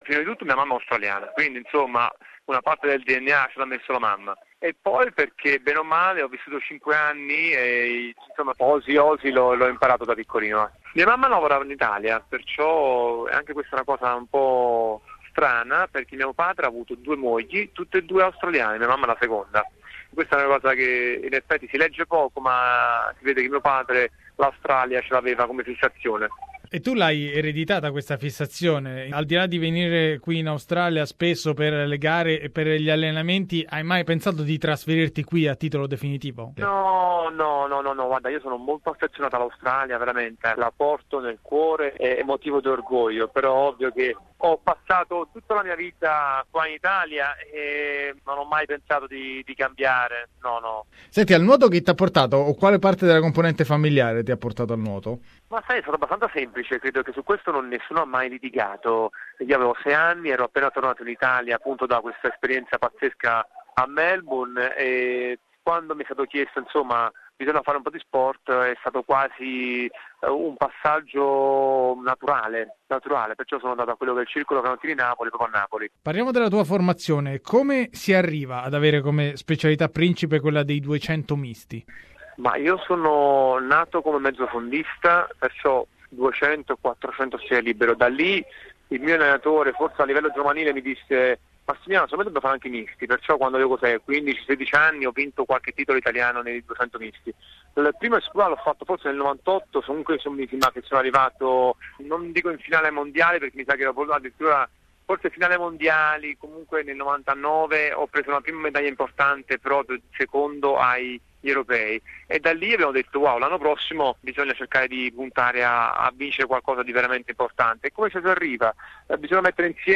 Interview with former Italian-Australian swimmer Massimiliano Rosolino, a 2000 Olympics gold medalist. He talks about his Sydney's triumph, Ian Thorpe, the Rio Olympics, his training days at Nunawading Swimming Club.